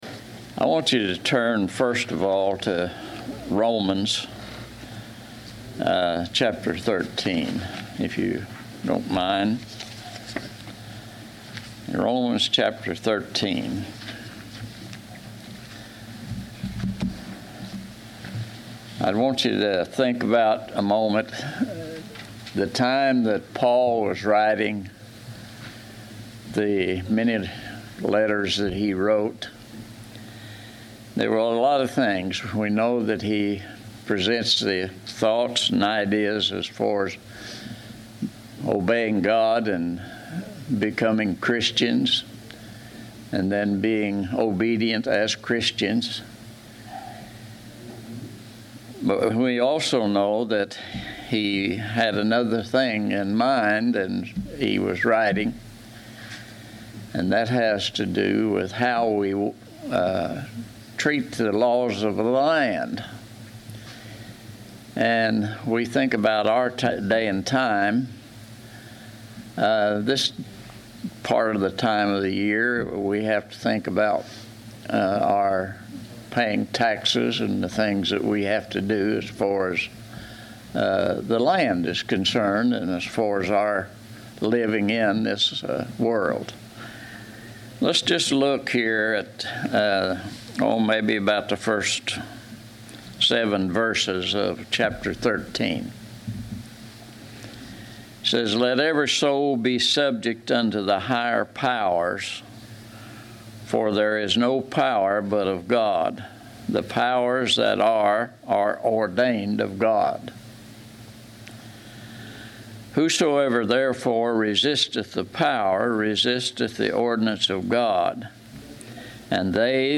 Service Type: Sunday 10:00 AM